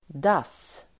Uttal: [das:]